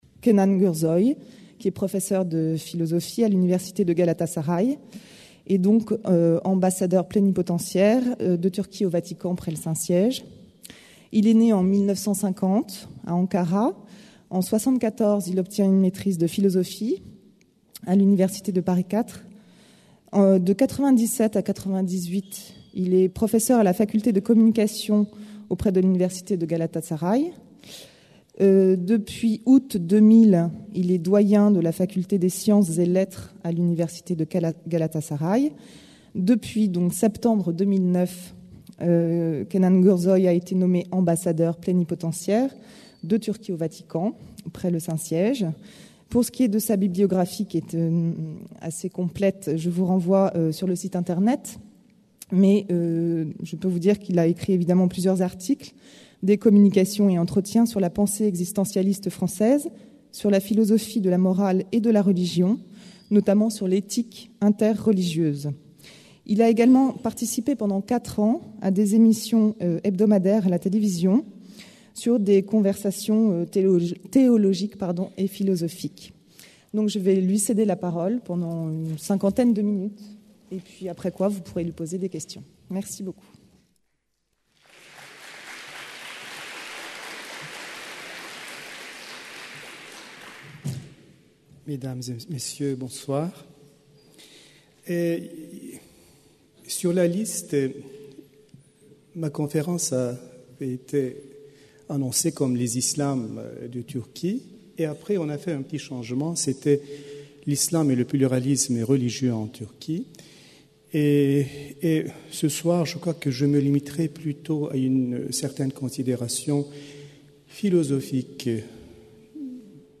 Une conférence du cycle La Turquie, aujourd'hui demain L'Islam et le pluralisme en Turquie par Kenan Gürsoy Ambassadeur plénipotentiaire de Turquie près le Saint Siège